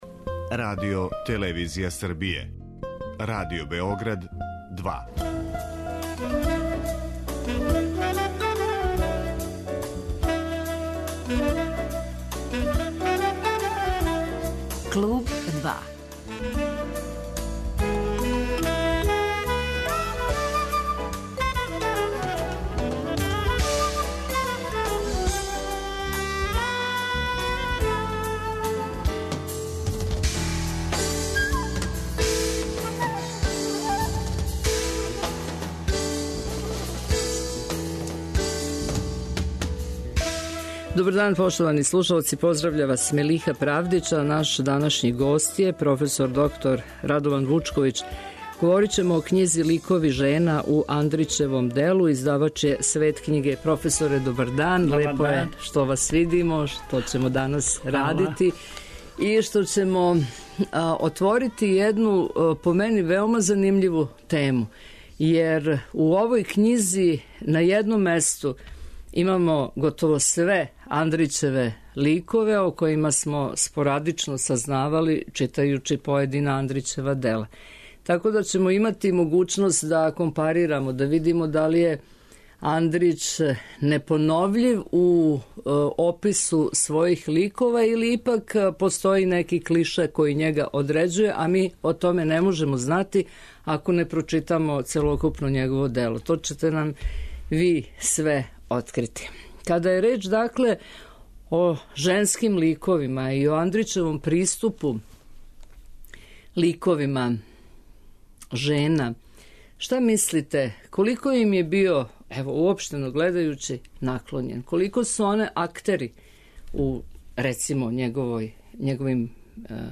Наш данашњи гост је велики познавалац Андрићевог дела, а данас ћемо разговарати о овој књизи, његовом истраживачком поступку и о значају женских личности у делу Иве Андрића.